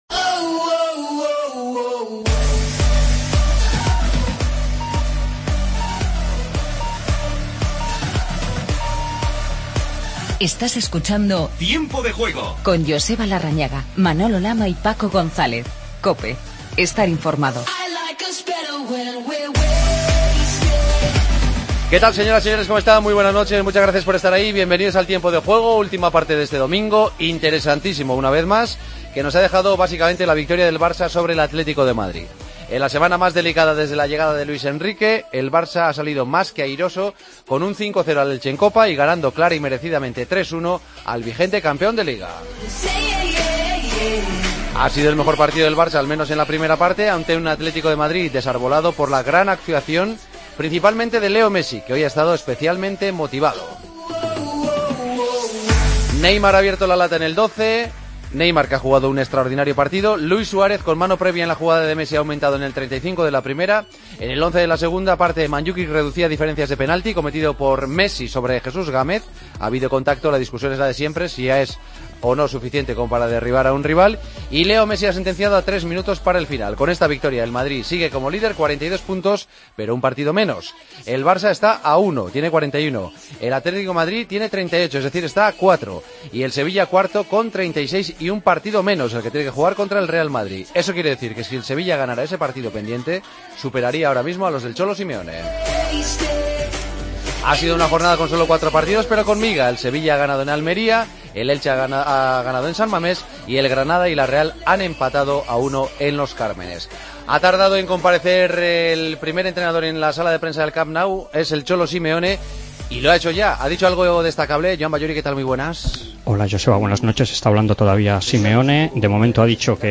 Paso por el Camp Nou para escuchar a los protagonistas del Barça - Atlético de Madrid. Ruedas de prensa de Luis Enrique y Simeone. Pasaron por zona mixta Mascherano e Iniesta. Entrevista a Juanfran Torres.